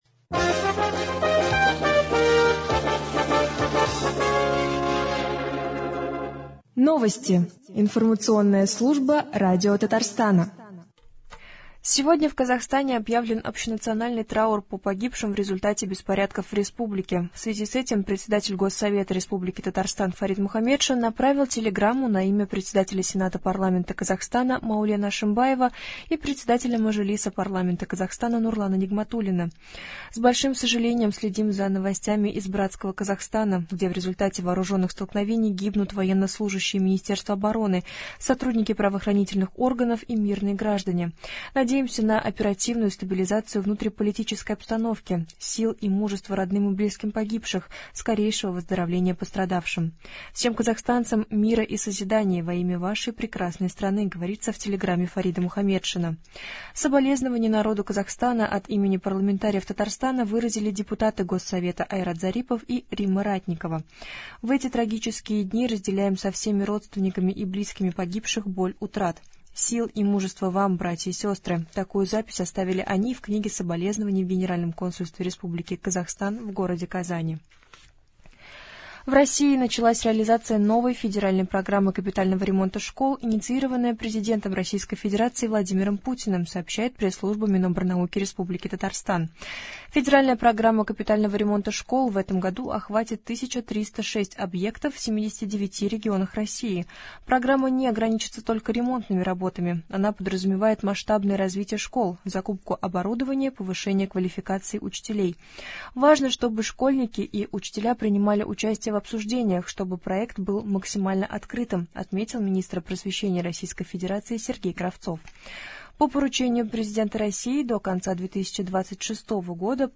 Новости (10.01.22)